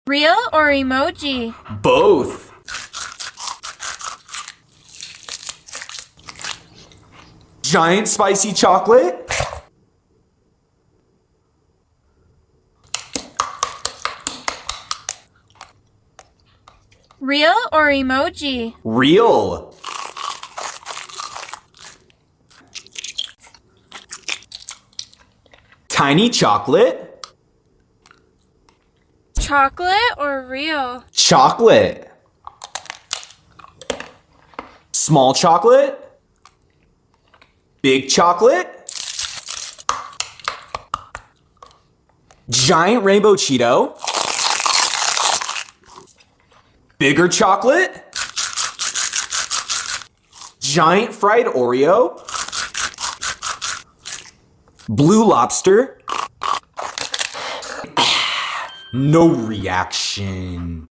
Real OR Emoji Food ASMR sound effects free download